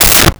Car Door Close 02
Car Door Close 02.wav